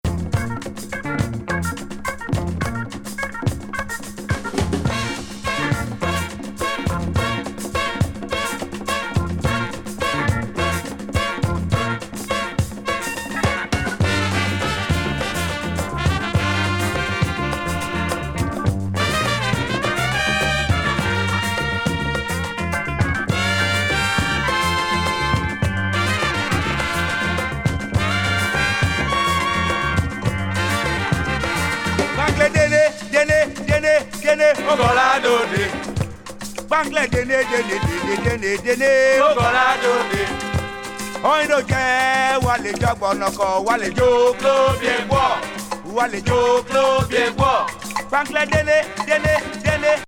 アフロ・カリビアン・